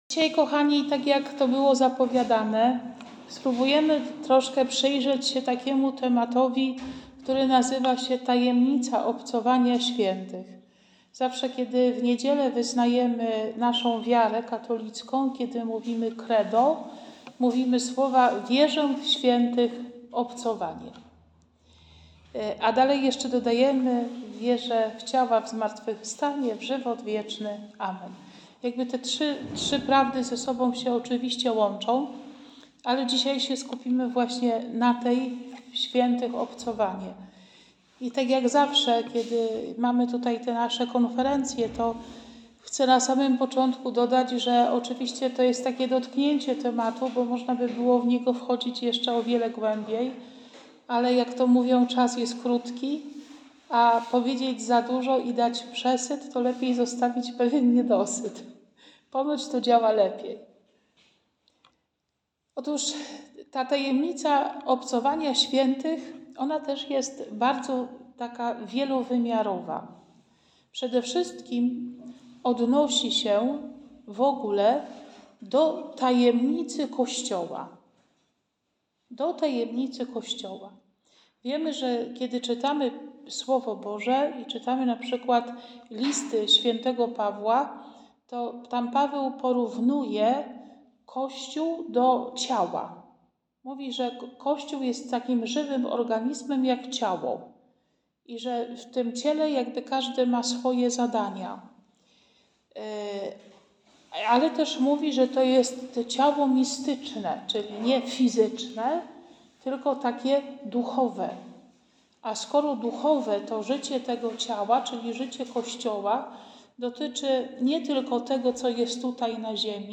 Kolejna, ciekawa konferencja